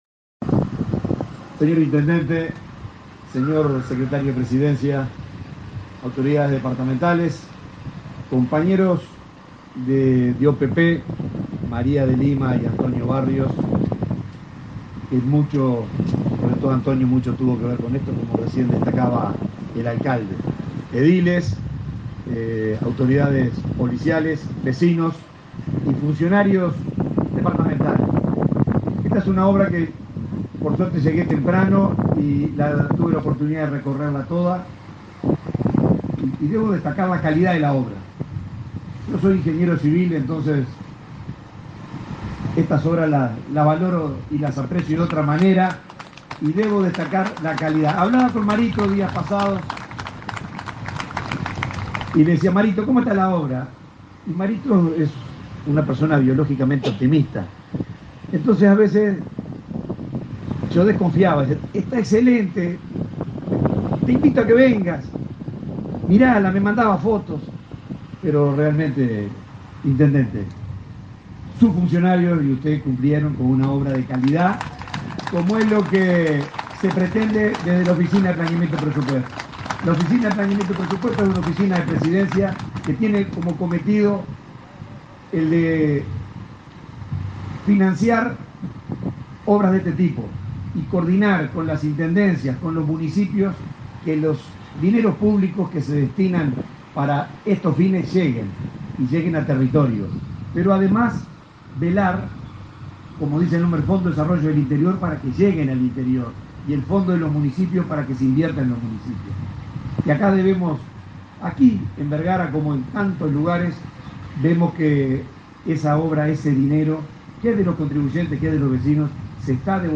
Conferencia de prensa por inauguración de obras en la localidad de Vergara
El secretario de Presidencia de la República, Álvaro Delgado, participó este 10 de marzo en la inauguración de obras viales y pluviales en la localidad de Vergara, realizadas por la Oficina de Planeamiento y Presupuesto (OPP) y la Intendencia de Treinta y Tres.